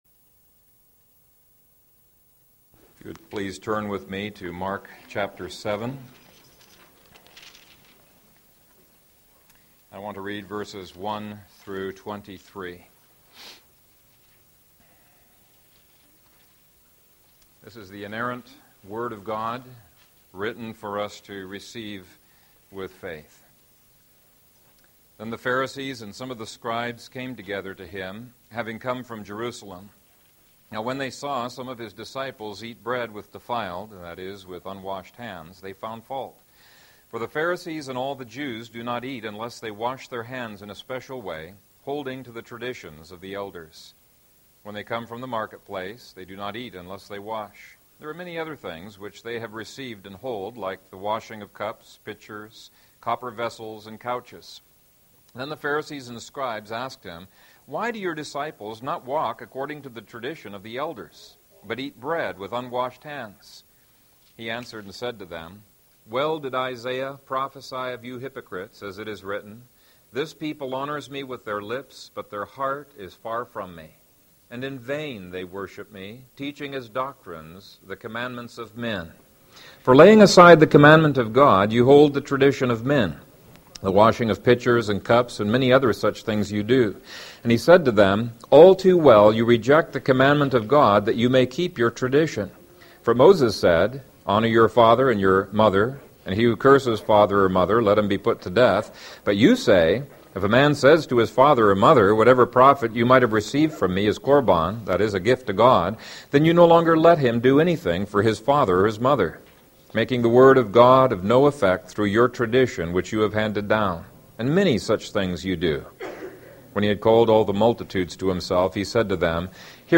Easily Led Into Sin | SermonAudio Broadcaster is Live View the Live Stream Share this sermon Disabled by adblocker Copy URL Copied!